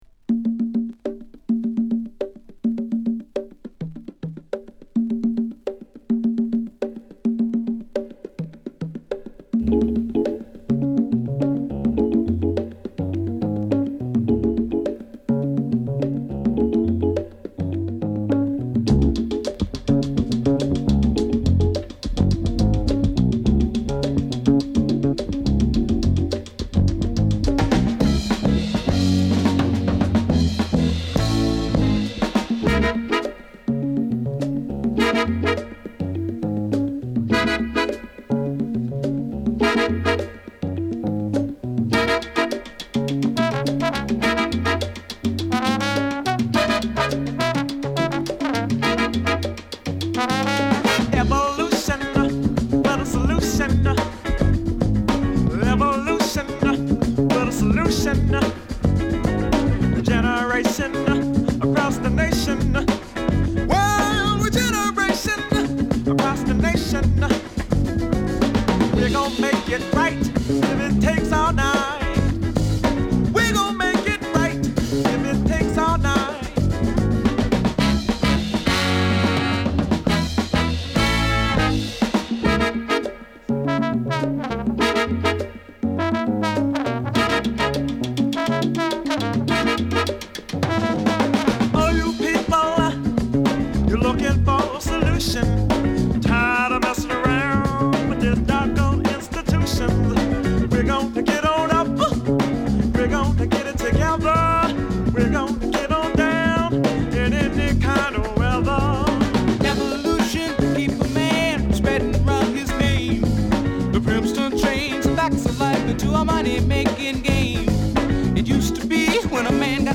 パーカッションブレイクをイントロに配したエディットver.で、DJにも使い勝手バッチリ！
オリジナルテープからのリマスタリングで音圧／鳴りも文句無しです。